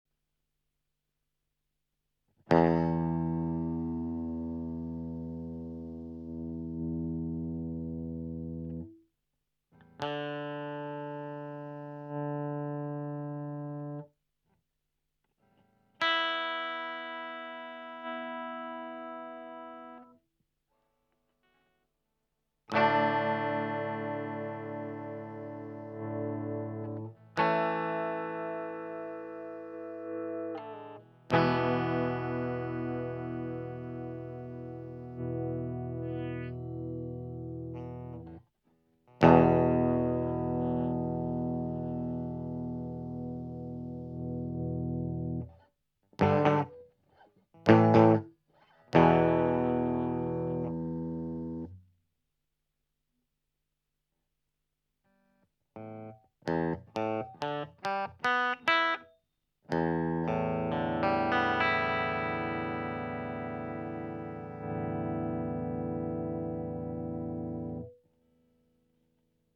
Der Sound kommt normal, dann nach etwa 2 Sekunden oder 3 wird es lauter, als würde man einen Booster einschalten.
Selbst bei ganz cleaner Einstellung.
Wie schonmal geschrieben, dauert der Lautstärkeanstieg bei tiefen Tönen länger, als bei hohen Tönen.
An einem gewissen Punkt springt die Lautstärke leicht nach oben.